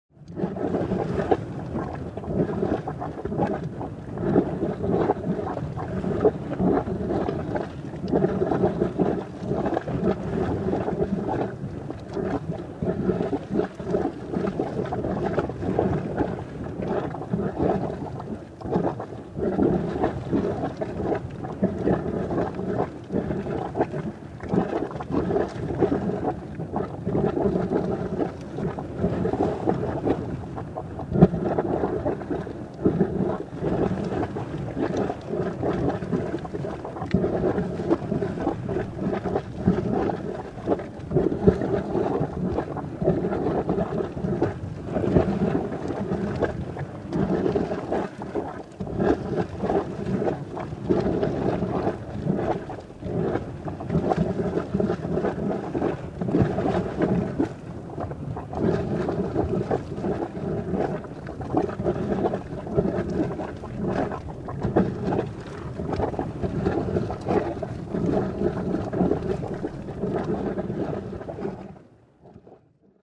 Звуки гейзеров
Гейзер безмятежный